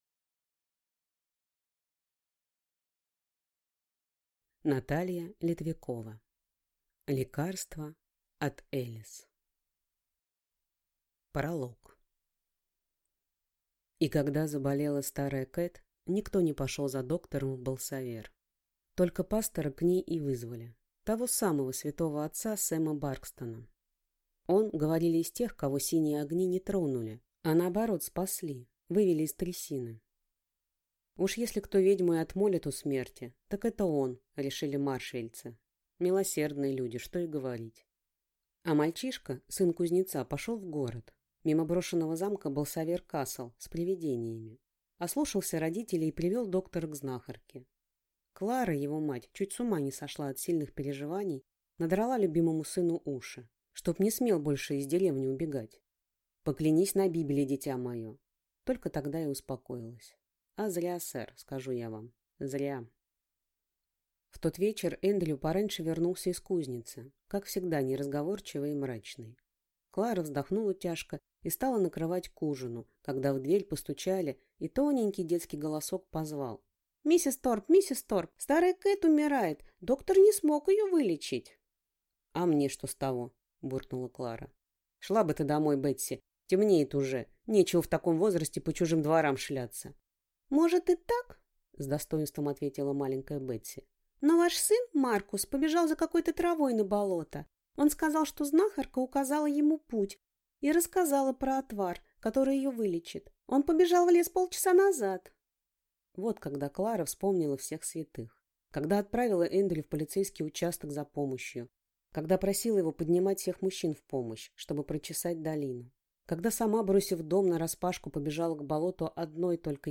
Аудиокнига Лекарство от Элис | Библиотека аудиокниг